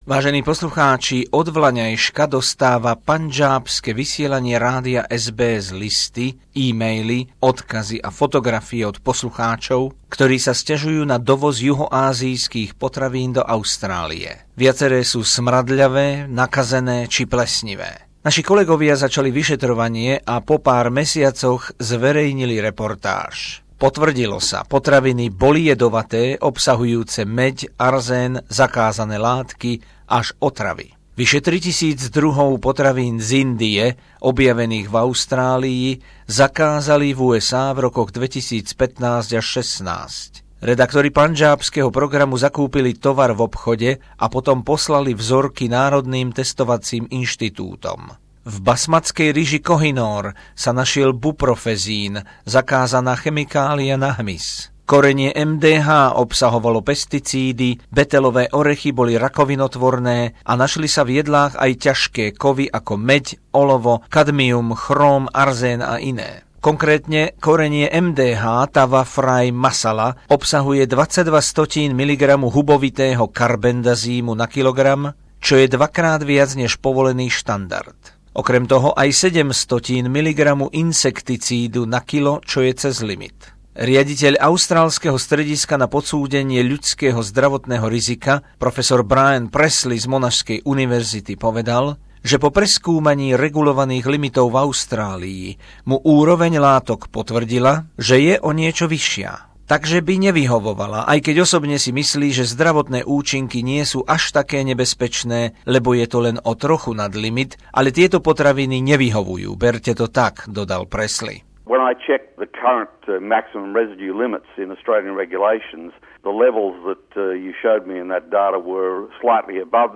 Reportáž pandžábskeho programu Rádia SBS o dovoze nebezpečných potravín z južnej Ázie do Austrálie